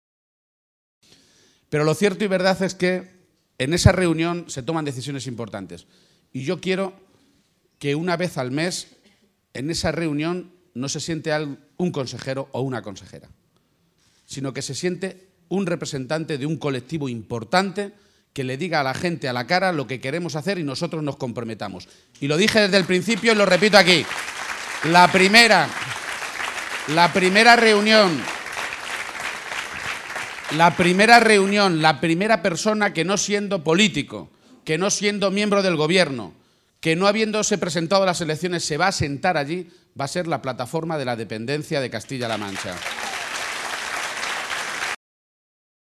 García-Page se pronunciaba de esta manera esta mañana en Azuqueca de Henares, primera de las tres localidades de la provincia de Guadalajara que ha visitado junto al secretario general del PSOE, Pedro Sánchez.